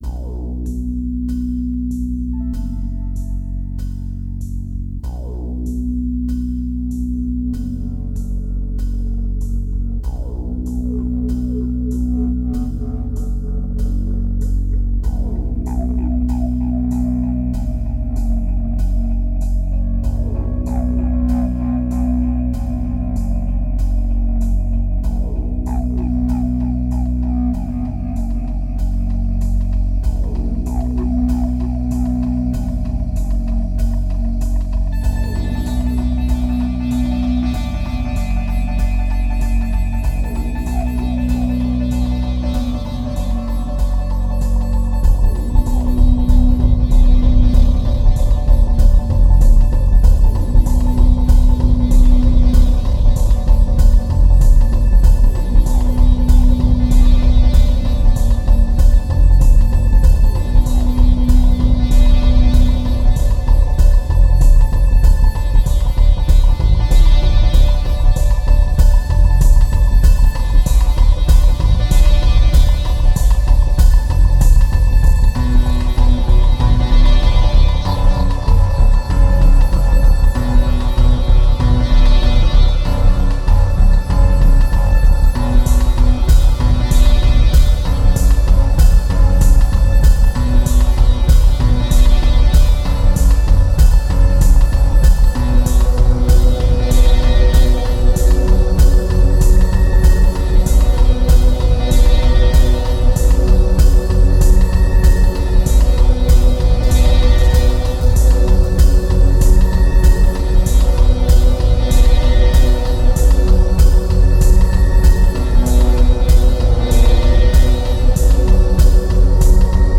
2351📈 - 0%🤔 - 96BPM🔊 - 2010-11-25📅 - -178🌟